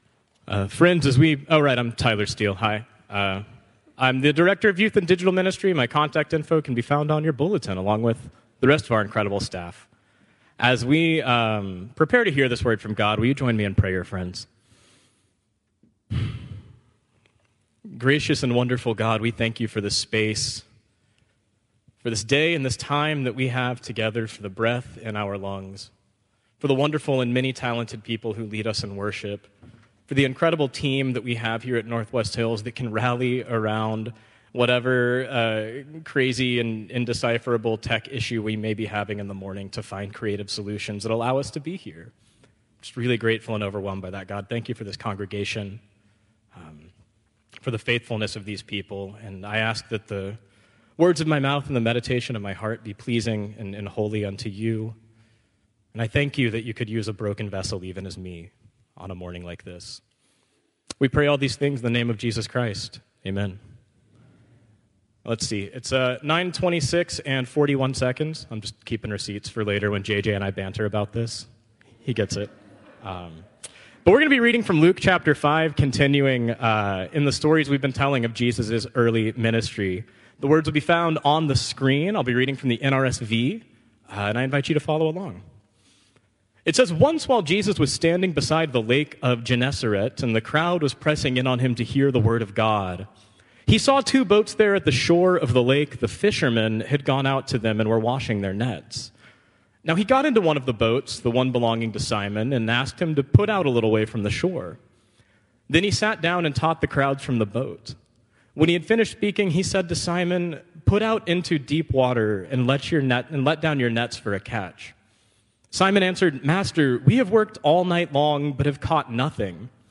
Contemporary Service 2/9/2025